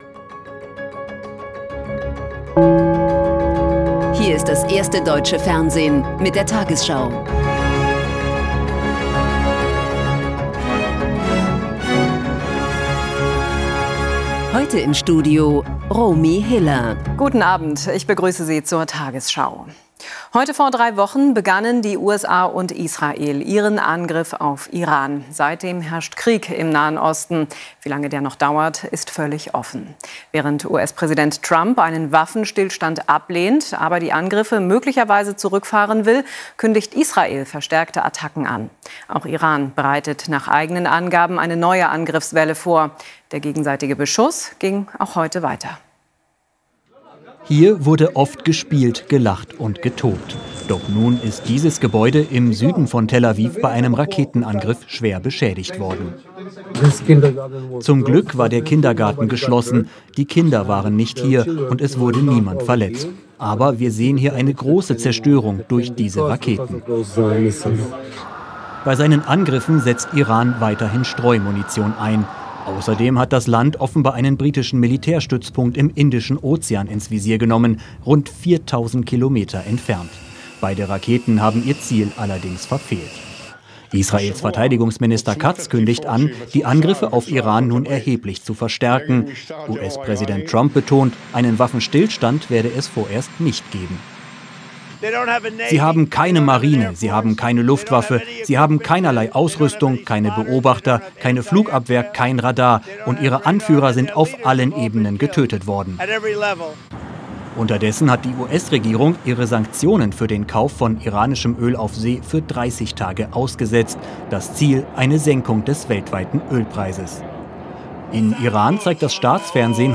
tagesschau 20:00 Uhr, 21.03.2026 ~ tagesschau: Die 20 Uhr Nachrichten (Audio) Podcast